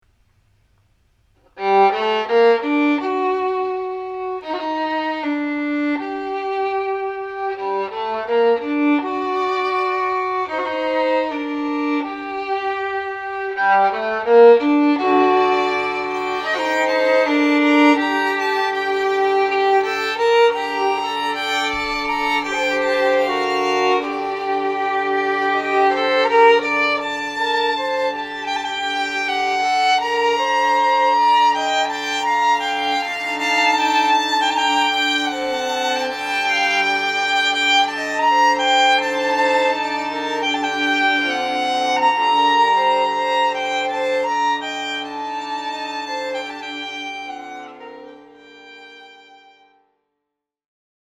Here is my Friday morning violin offering.